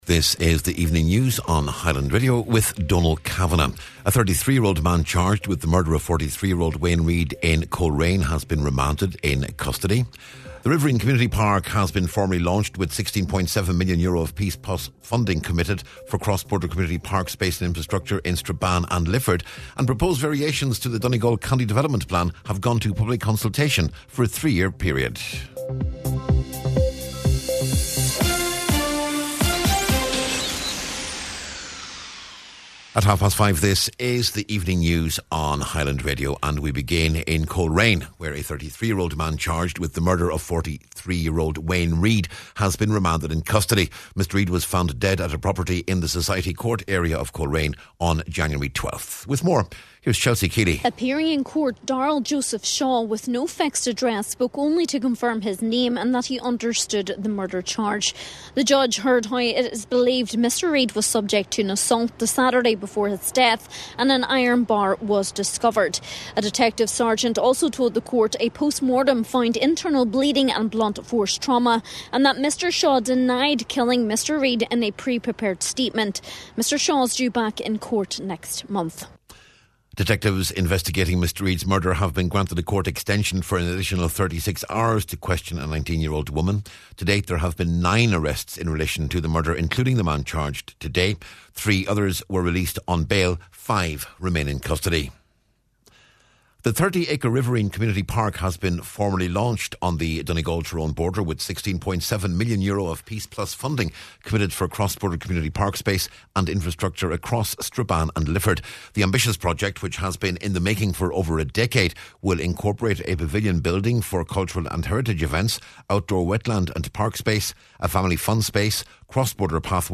Evening News, Sport and Obituaries on Monday January 19th